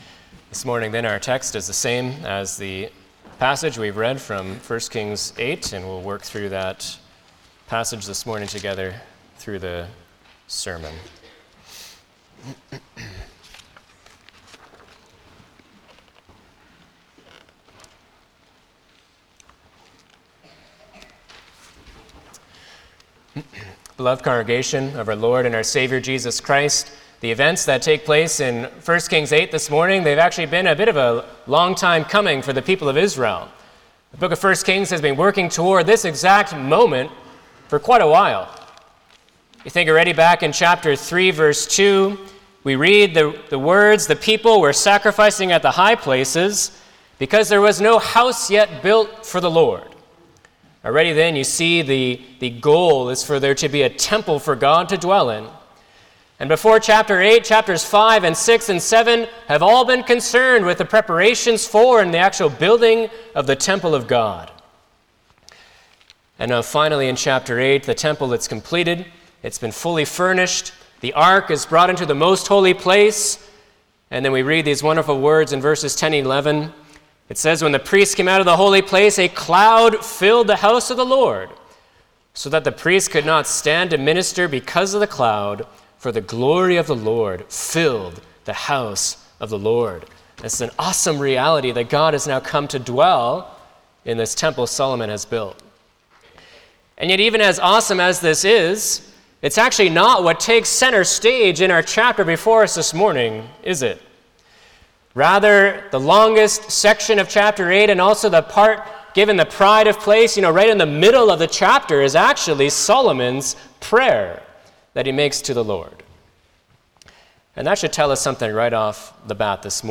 Passage: 1 Kings 8: 1-53 Service Type: Sunday morning
07-Sermon.mp3